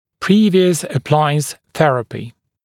[‘priːvɪəs ə’plaɪəns ‘θerəpɪ][‘при:виэс э’плайэнс ‘сэрэпи]предыдущее аппаратурное лечение